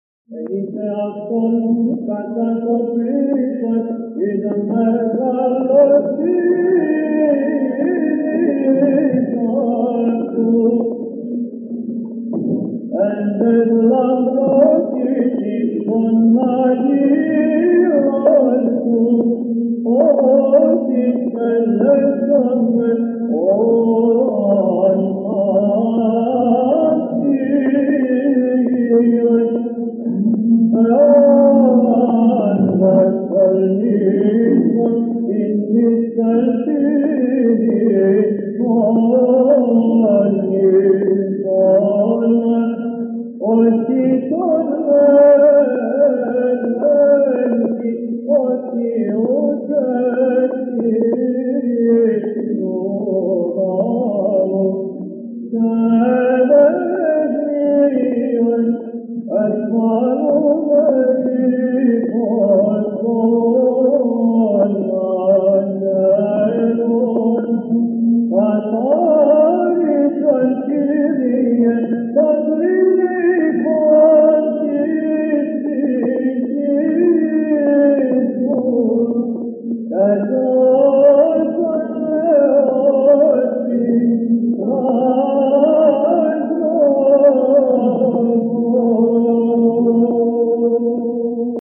Ἐν ταῖς λαμπρότησι, Στανίτσας — ἔτος 1959, (ἠχογρ. Μεγ. Δευτέρα ἑσπέρας)